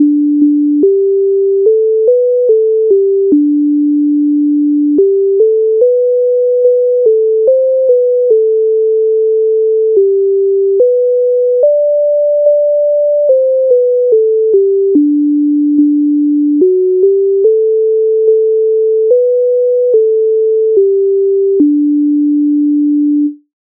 MIDI файл завантажено в тональності G-dur
Ой гай мати гай Українська народна пісня з обробок Леонтовича с. 150
Ukrainska_narodna_pisnia_Oj_haj_maty_haj.mp3